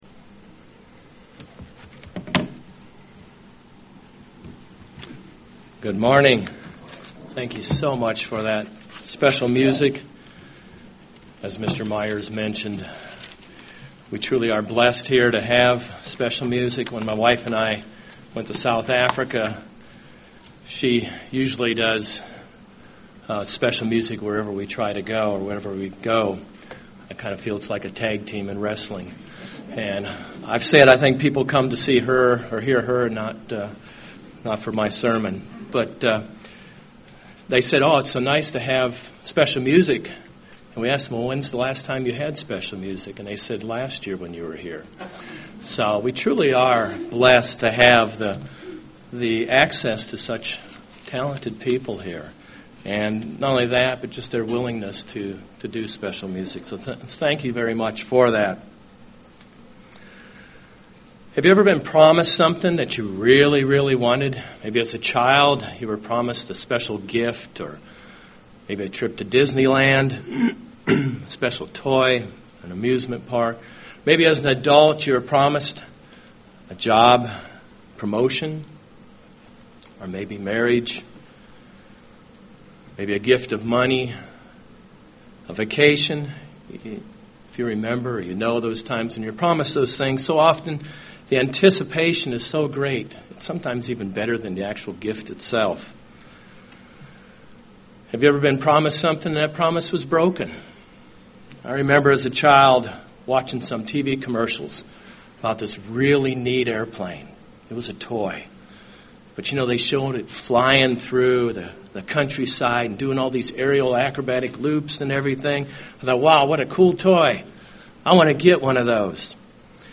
Given in Cincinnati East, OH
UCG Sermon Studying the bible?